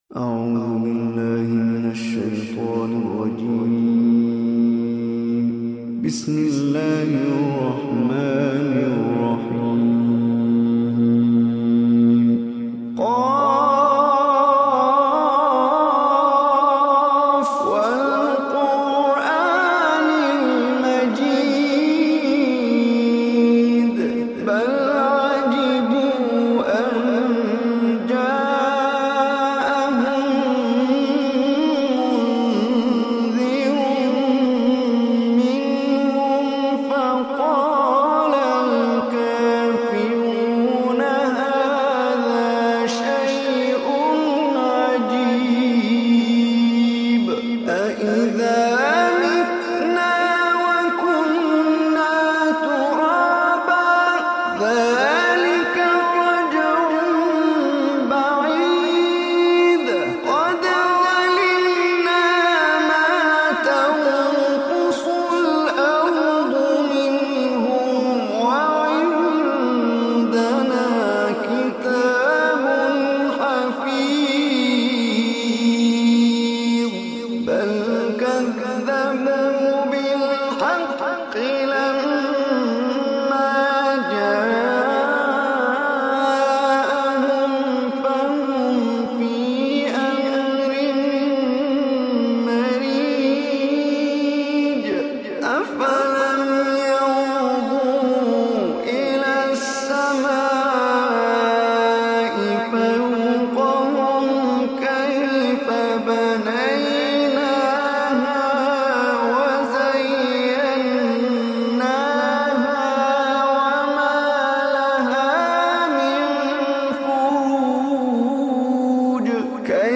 Surah Qaf Recitation